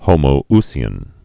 (hōmō-sē-ən, -zē-)